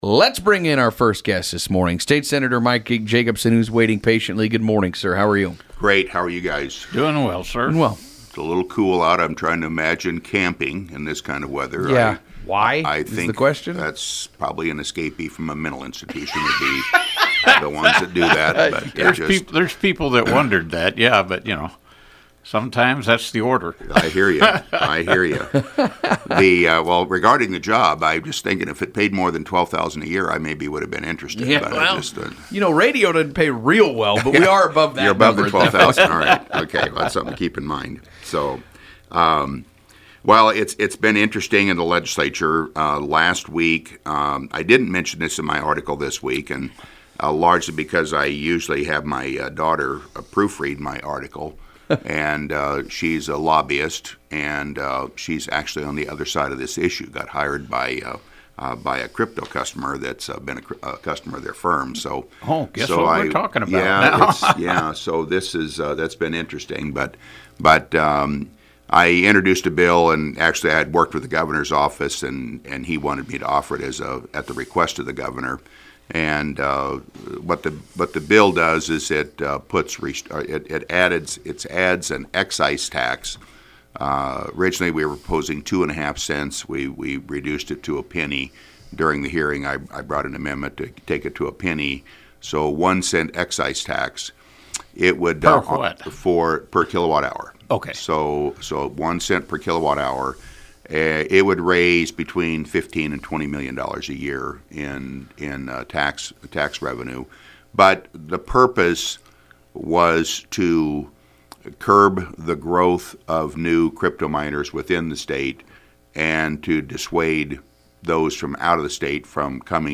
State Senator Mike Jacoboson joined Huskeradio’s Mugs in the Morning on Monday, February 17th to discuss a few bills being introduced at the Nebraska Legislature.